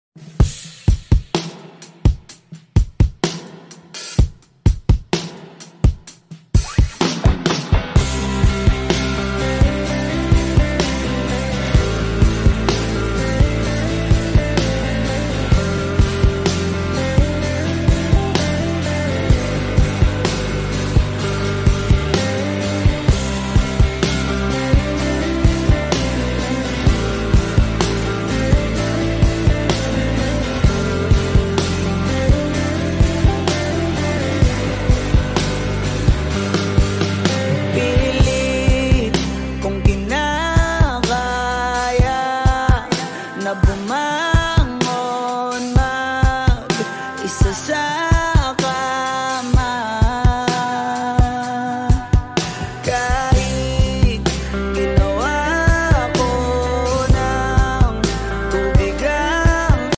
(2025 Studio Version)